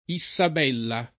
iSSab$lla], una delle eroine dell’«Orlando furioso»